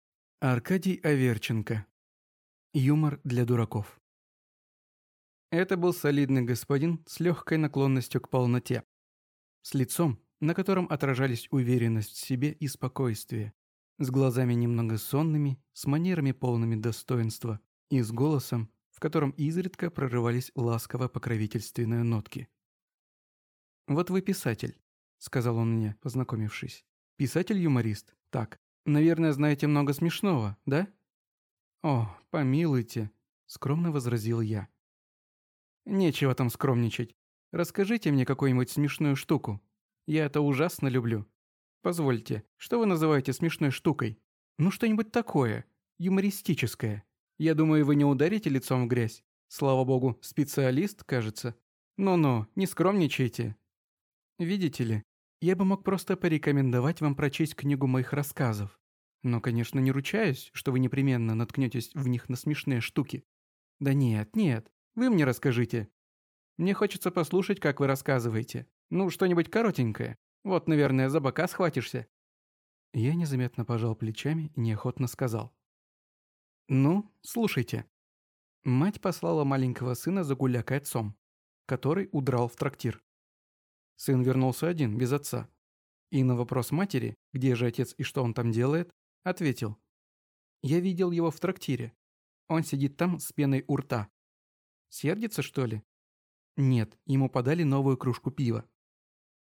Аудиокнига Юмор для дураков | Библиотека аудиокниг